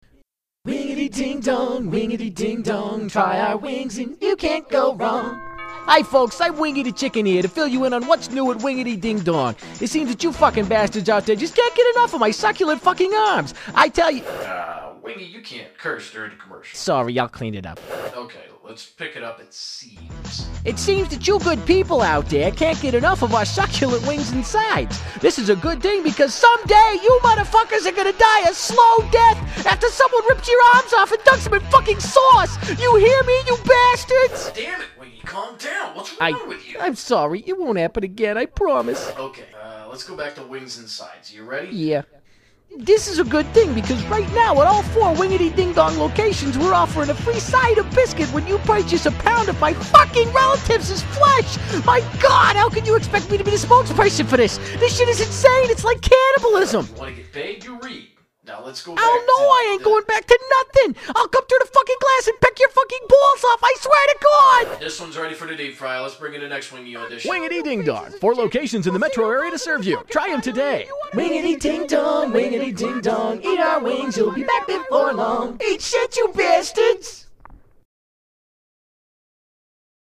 Seriously, before it was there, and that was fine, but now there are all of these curse words and better effects and production and I HAD TO SHARE IT WITH YOU BECAUSE...well, just because. Here you go, friends and neighbors...The new, improved Wingity Ding Dong...
Like, 20 or 30 "f-words" just as a start. NO BLEEPS OR CENSORING!